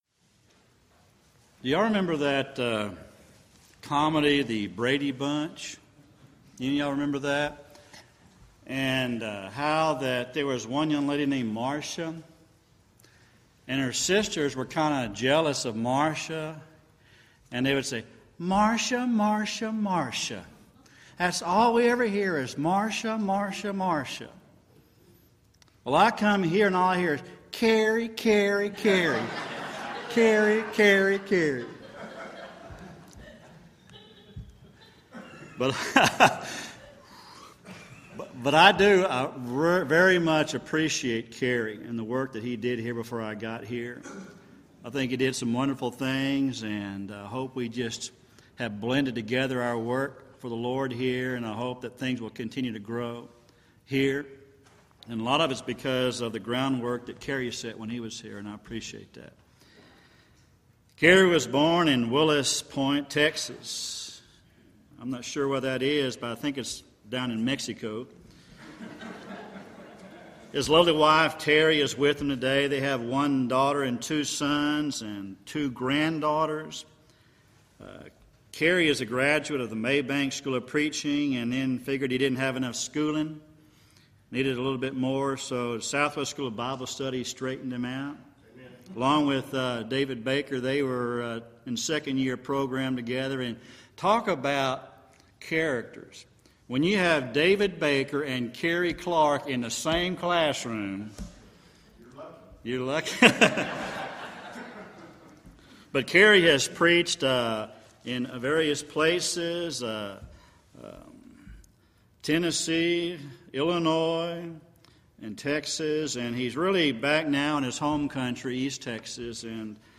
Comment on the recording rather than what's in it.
3rd Annual Back to the Bible Lectures